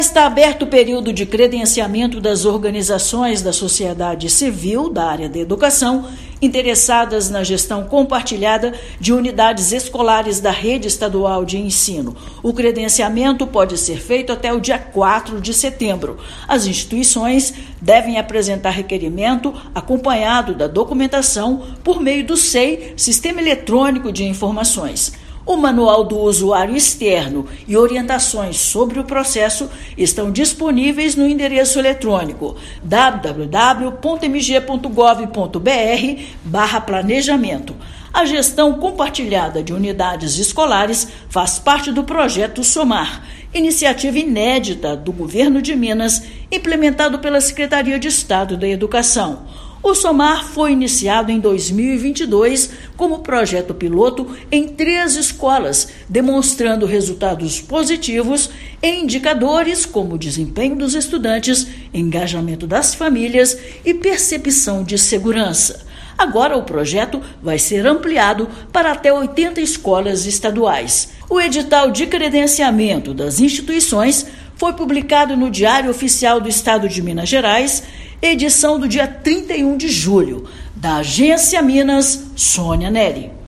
Organizações da Sociedade Civil interessadas no modelo de gestão compartilhada de unidades escolares devem enviar documentação necessária pelo Sistema Eletrônico de Informações. Ouça matéria de rádio.